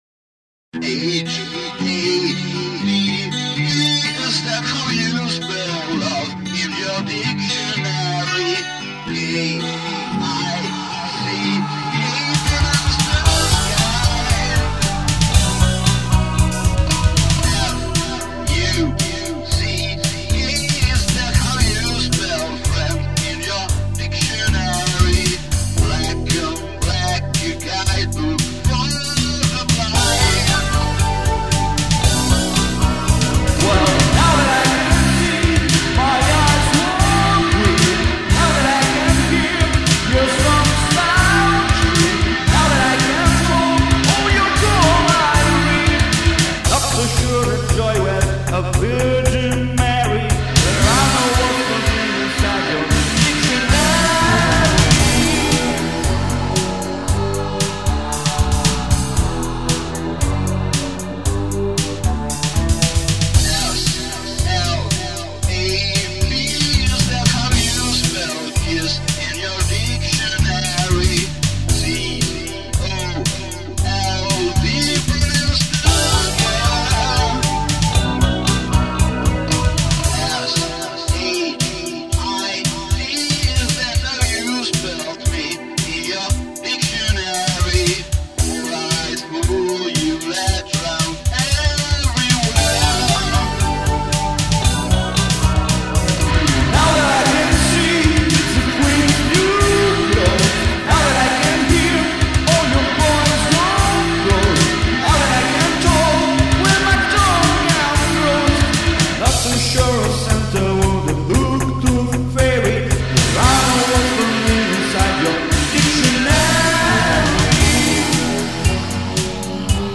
guitar, keyboards and voices
drums and bass